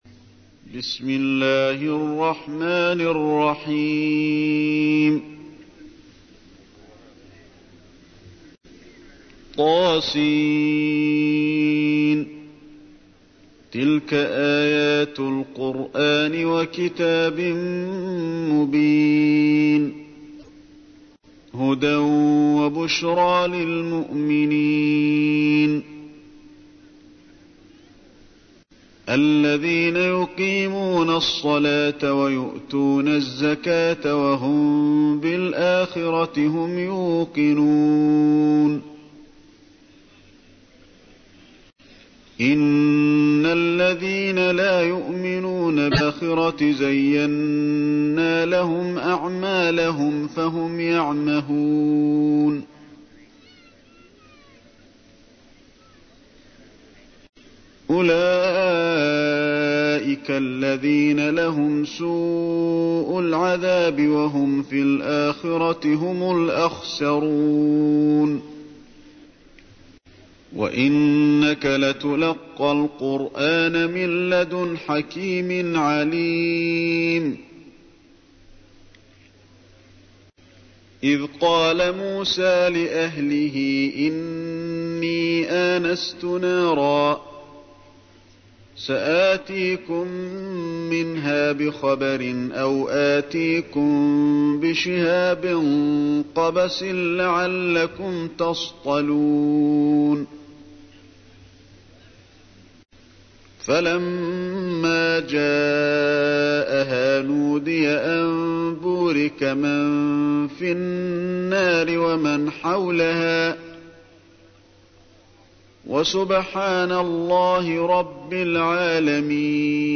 تحميل : 27. سورة النمل / القارئ علي الحذيفي / القرآن الكريم / موقع يا حسين